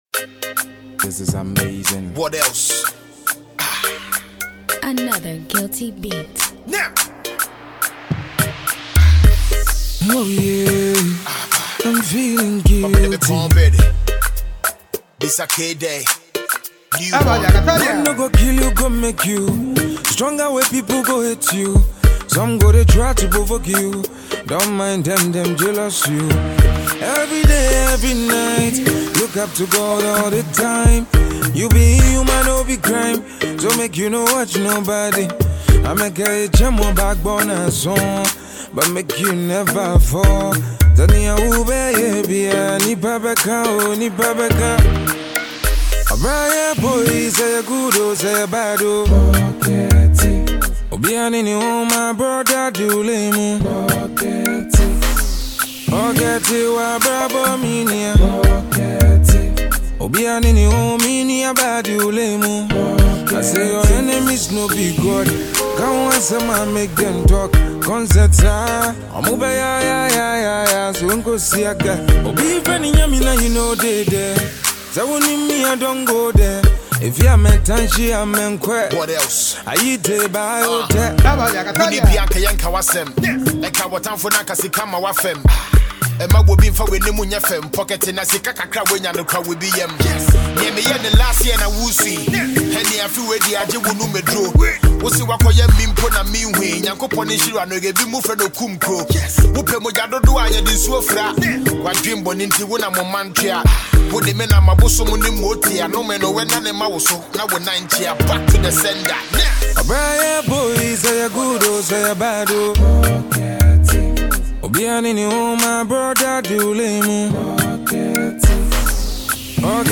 Highlife is still very much alive today
rapper